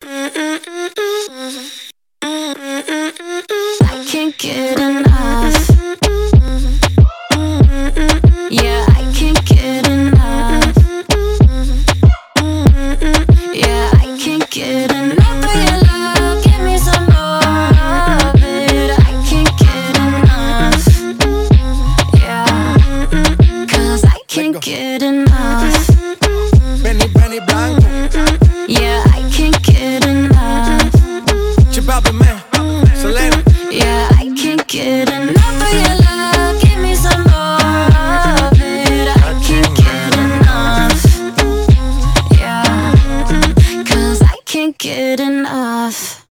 заводные , dancehall
dance pop
moombahton , реггетон , танцевальные